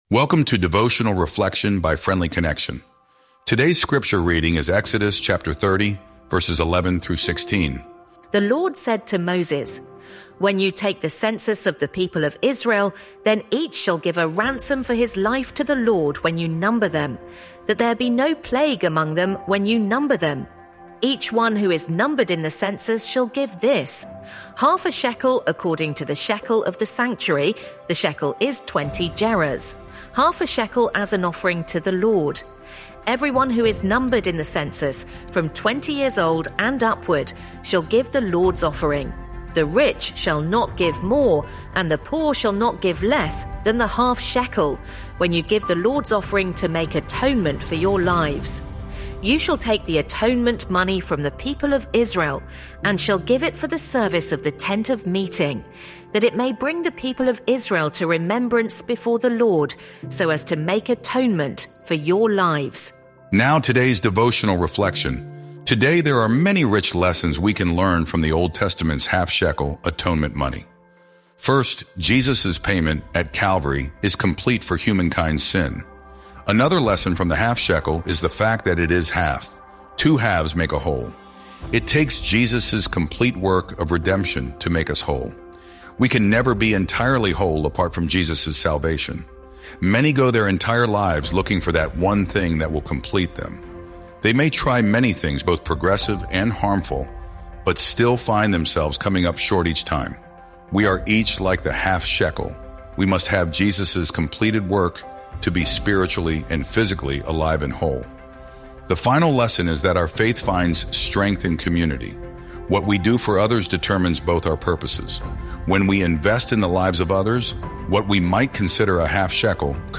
Sermons | Honey Creek New Providence Friends Church